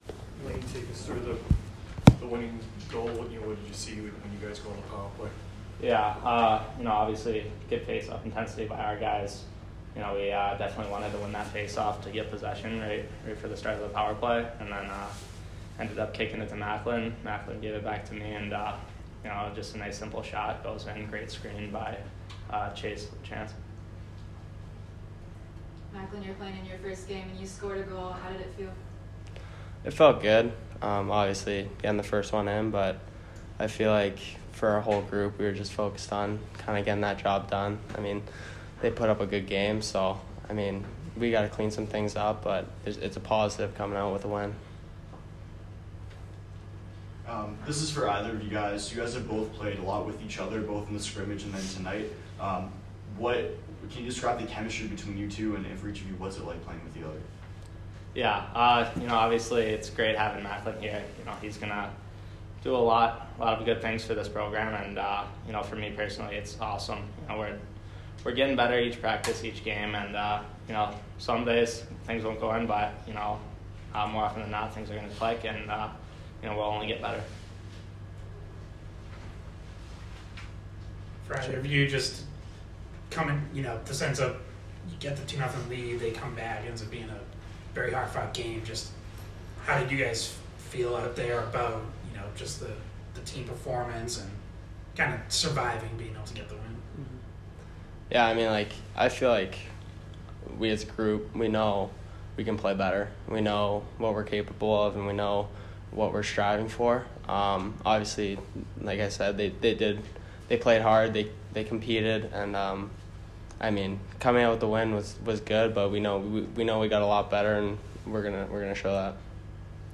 Lane Hutson and Macklin Celebrini - Men's Ice Hockey / Bentley Postgame Interview (10-7-23) - Boston University Athletics